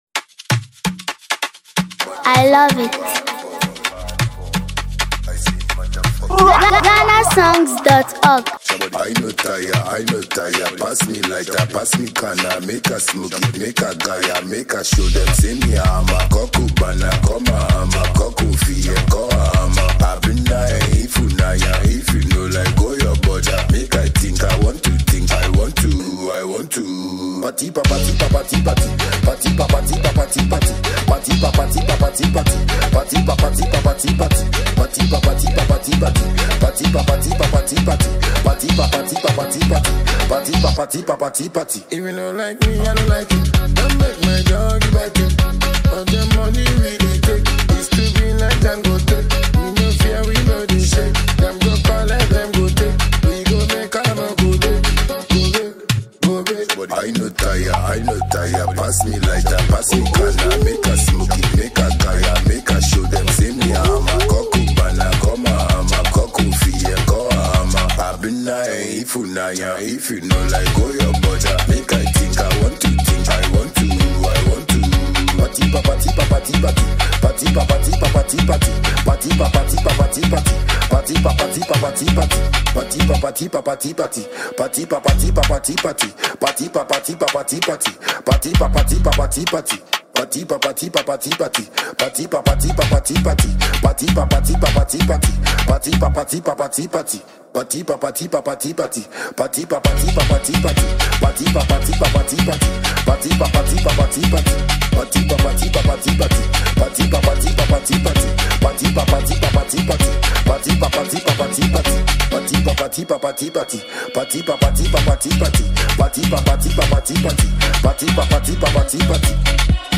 energetic banger